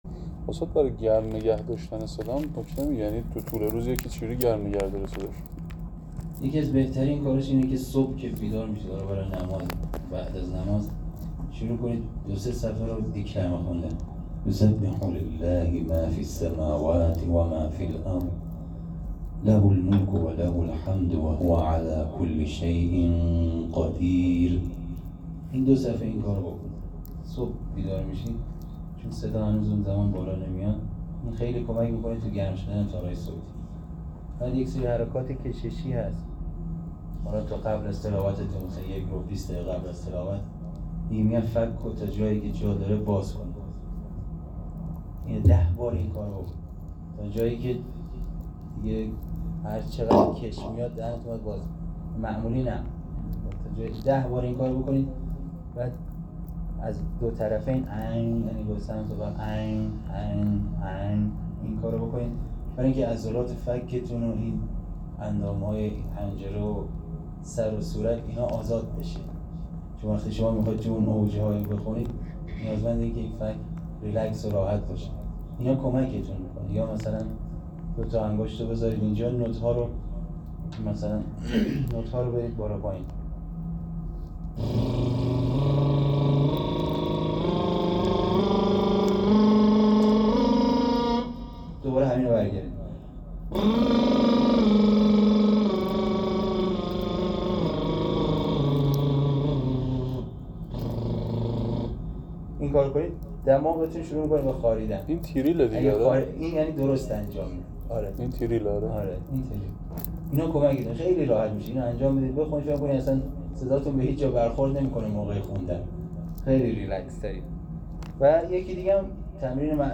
جلسه قرآن استان البرزبه گزارش ایکنا، کارگاه تخصصی قرائت قرآن کریم استان البرز، شامگاه گذشته، هفتم آذرماه با حضور تعدادی از قاریان و اساتید ممتاز و فعالان قرآنی استان در حسینیه‌‌ امام خمینی(ره) دفتر نماینده ولی‌فقیه در استان البرز و امام‌ جمعه کرج برگزار شد.